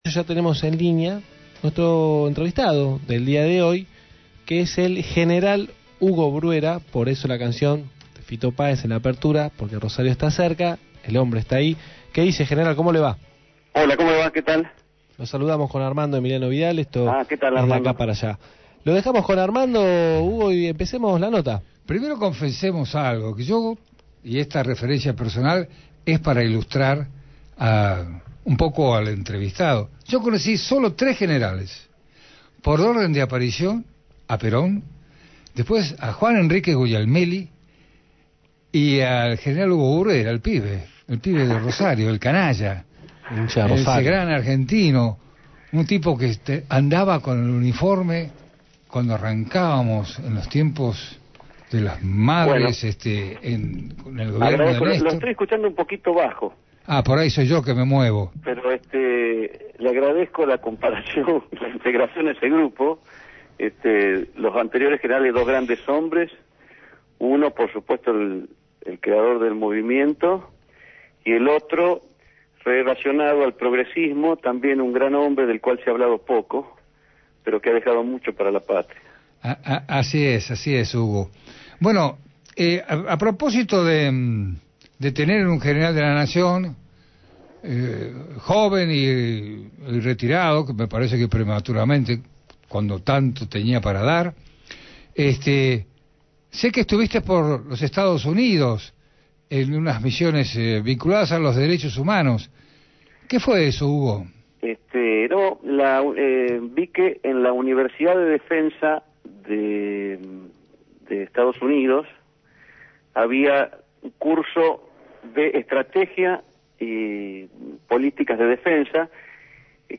Nacido en la ciudad santafesina de Rosario y confeso hincha “canalla”, el General del Ejército, Hugo Bruera, mantuvo un diálogo con De Acá para Allá, por Radio Gráfica. Los atentados en Francia, la política de derechos humanos desde las fuerzas armadas y el rol del nuevo gobierno después del ballotage del 22 de noviembre próximo, fueron algunos de los temas de la entrevista.